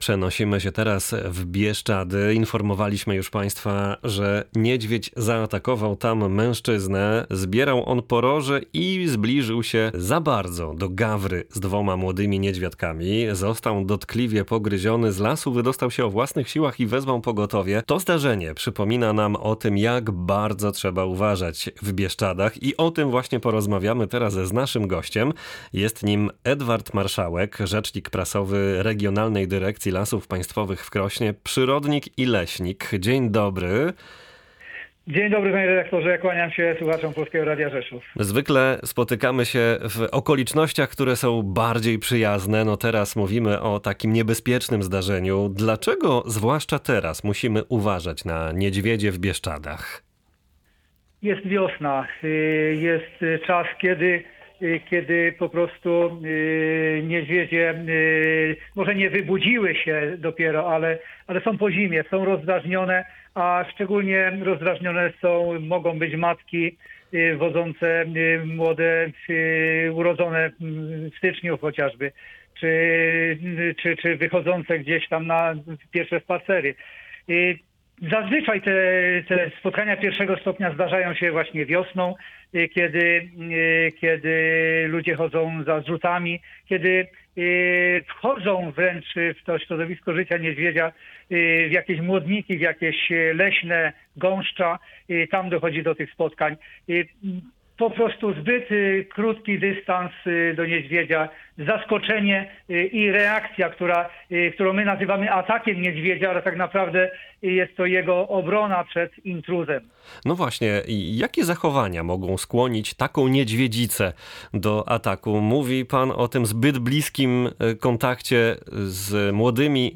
Zdaniem naszego gościa dobrze jest zasygnalizować swoją obecność w lesie.